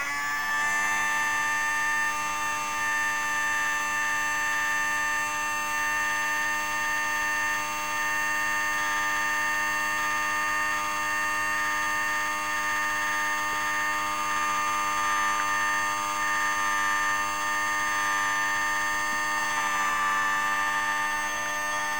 This is a recording of me talking to a miniature fan. Â I had to remove the blades as there was too much sound distortion for the recording.
Â The fan creates a “scream” even without the “propellers”.
There is some evidence of modulation in this recording of what is being said (reciting jack and jill). Â You don’t hear my voice because it was recorded directly into the computer not via a microphone but an in-line recording using “audacity”.
talking-at-fan-change-pitch-and-speed-jack-and-jillREC134.mp3